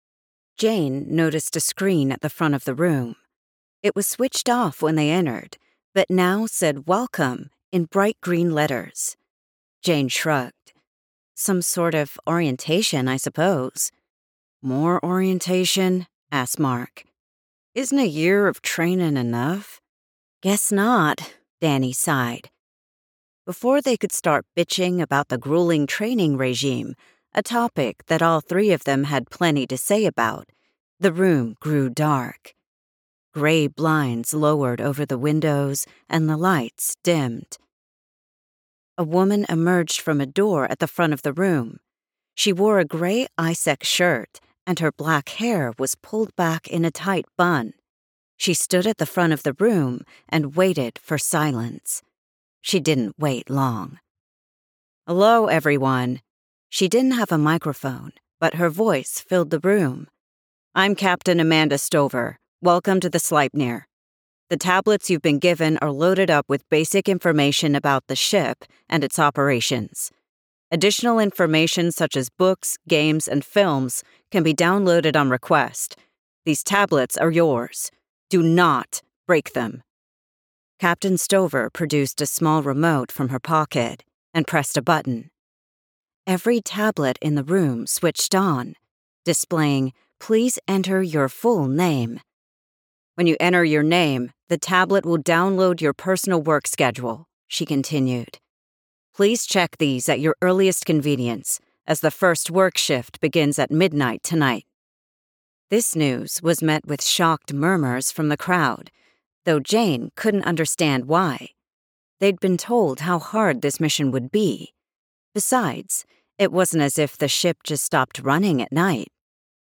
general-american Archives